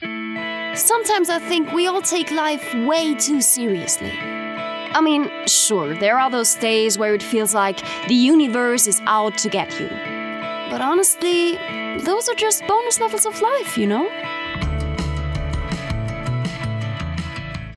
Booking Sprecherin